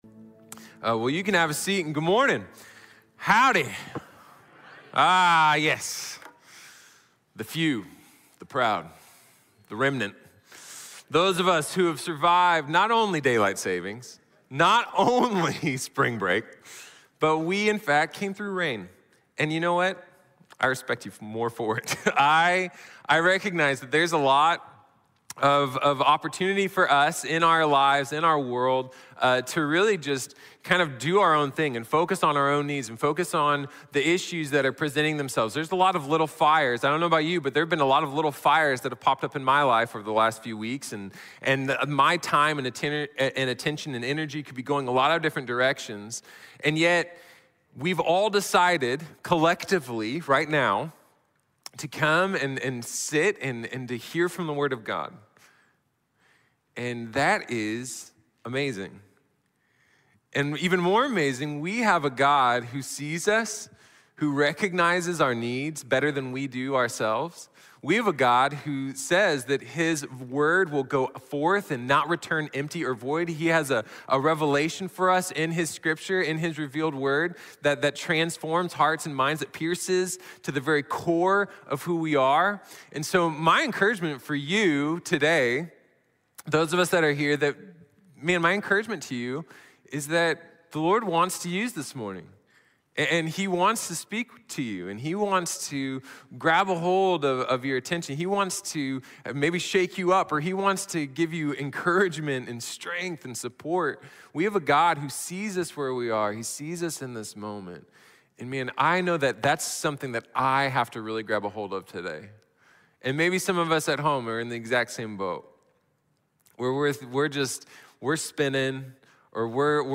Comunión | Sermón | Iglesia Bíblica de la Gracia